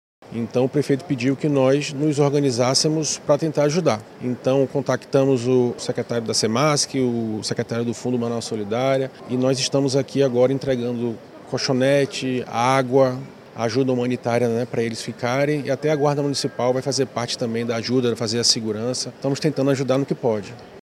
Já a prefeitura de Manaus disse que ofereceu ajuda humanitária a 88 brasileiros, com colchões e colchonetes, água e itens de limpeza, como explica o secretário municipal de Segurança Pública e Defesa Social (Semseg), Alberto Neto.